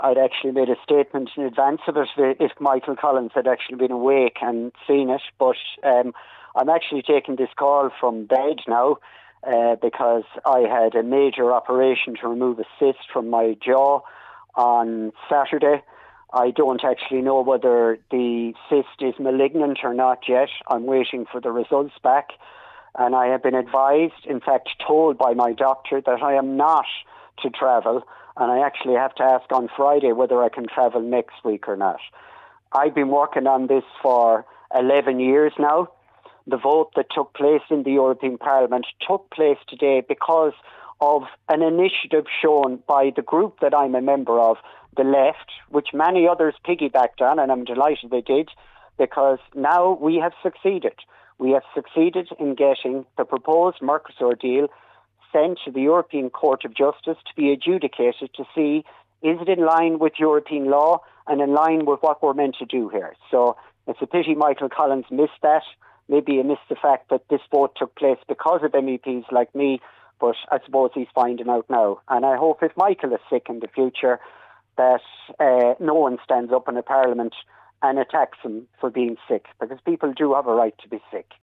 Meanwhile, MEP Luke Ming Flannagan confirmed to Highland Radio News that he was unable to travel due to medical advice following surgery and regrets that Deputy Collins made such a statement………………